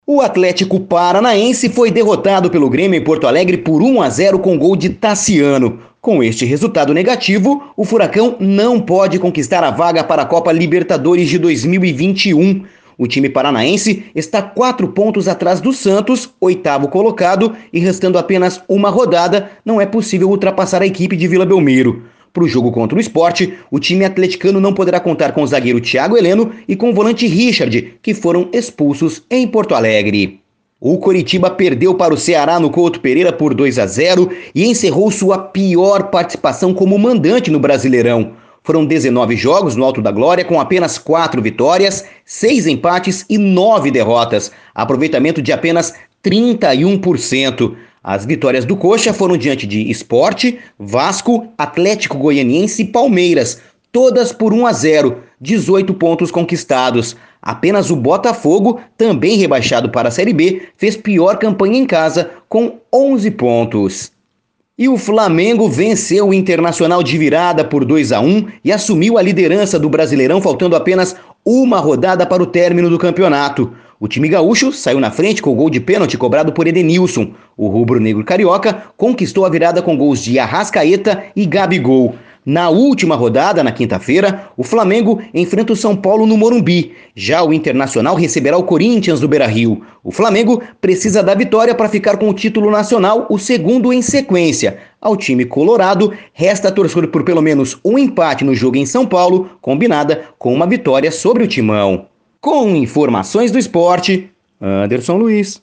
Giro Esportivo (SEM TRILHA)